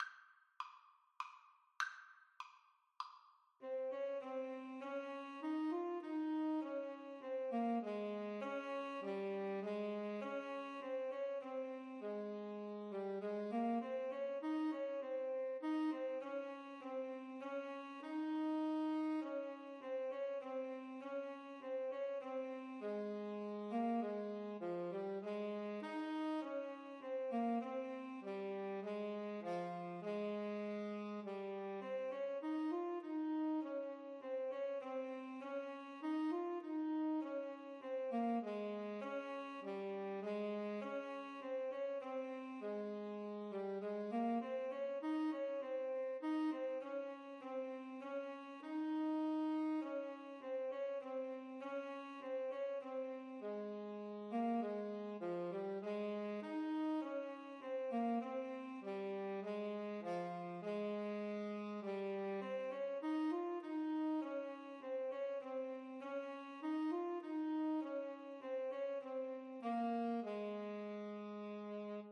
Christmas
Alto SaxophoneTenor Saxophone
Andante Moderato
3/4 (View more 3/4 Music)
Classical (View more Classical Alto-Tenor-Sax Duet Music)